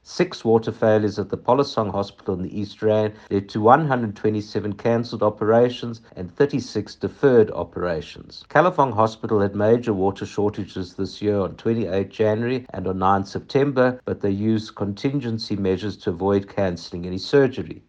The DA’s Jack Bloom says Edenvale Hospital had to cancel 117 operations because of seven periods with low pressure or no water at all, sometimes lasting as long as a week: